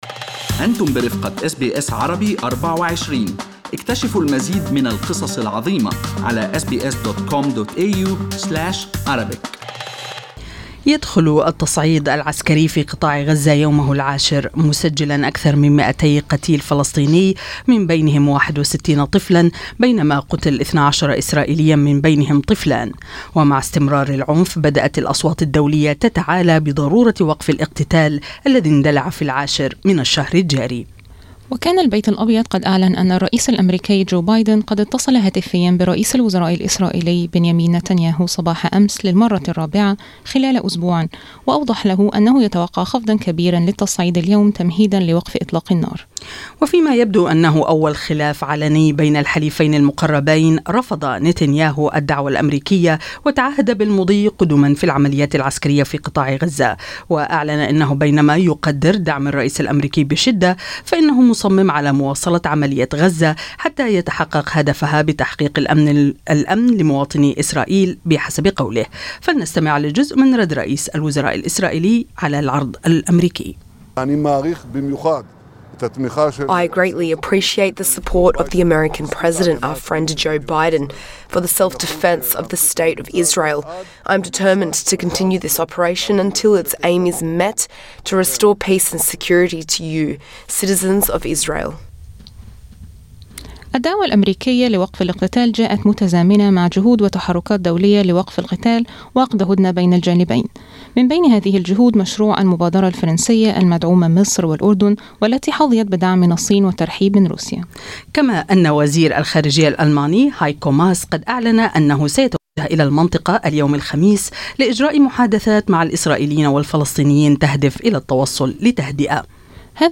فلنستمع لجزء من رد رئيس الوزراء الاسرائيلي على واشنطن